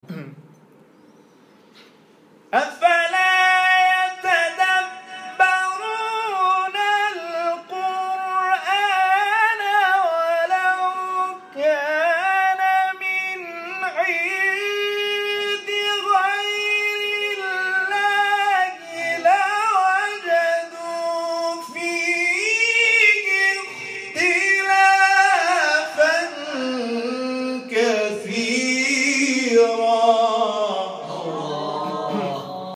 شبکه اجتماعی: فرازهای صوتی از تلاوت قاریان برجسته و ممتاز کشور را که به‌تازگی در شبکه‌های اجتماعی منتشر شده است، می‌شنوید.